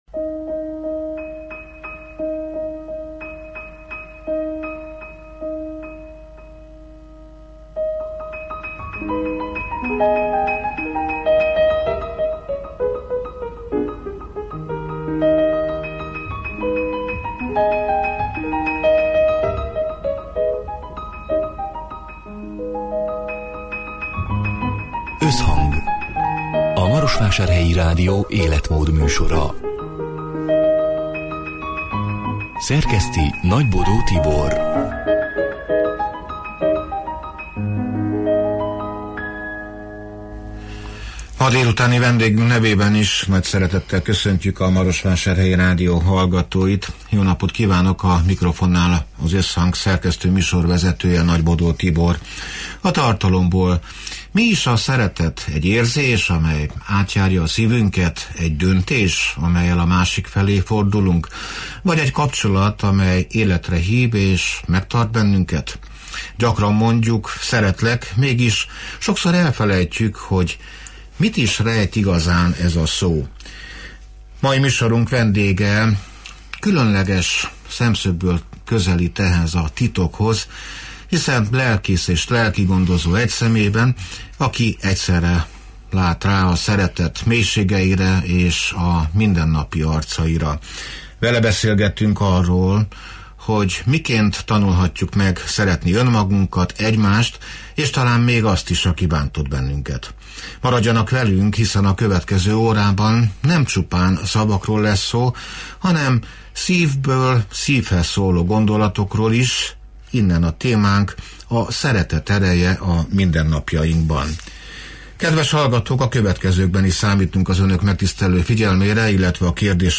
A Marosvásárhelyi Rádió Összhang (elhangzott: 2025. október 15-én, szerdán délután hat órától élőben) c. műsorának hanganyaga: